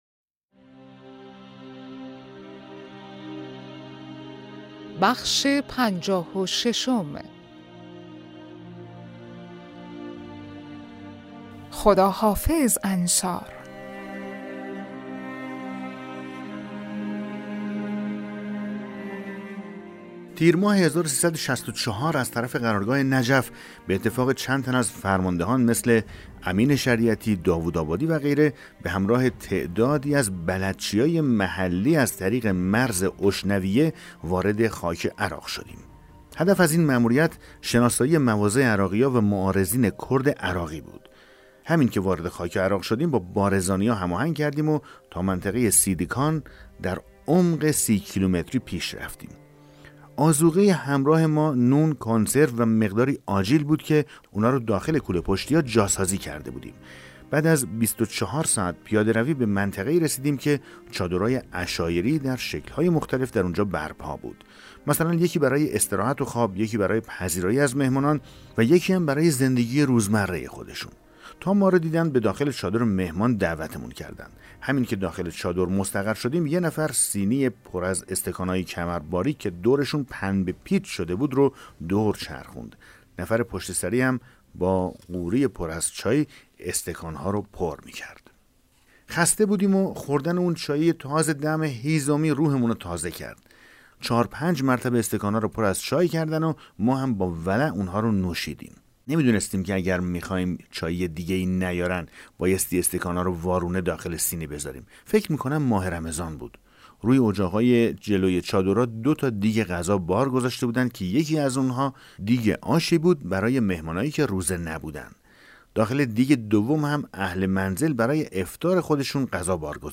کتاب صوتی پیغام ماهی ها، سرگذشت جنگ‌های نامتقارن حاج حسین همدانی /قسمت 56
بخش‌هایی از این کتاب ارزشمند را در همدان صداگذاری کرده اند.